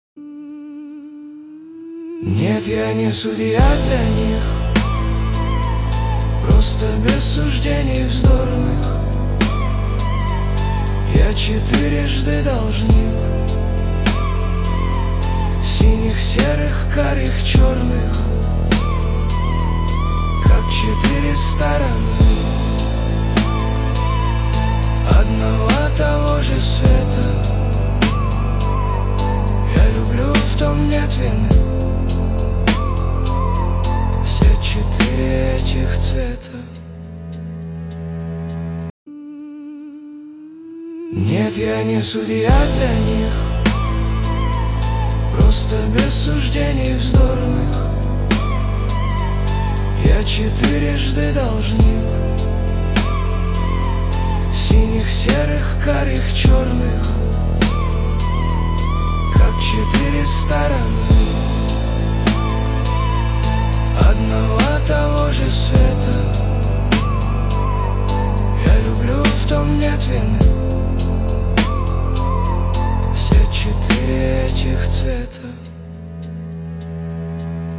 созданный с помощью искусственного интеллекта.
Русская AI музыка